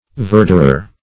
Search Result for " verderer" : The Collaborative International Dictionary of English v.0.48: Verderer \Ver"der*er\, Verderor \Ver"der*or\, n. [F. verdier, LL. viridarius, fr. L. viridis green.]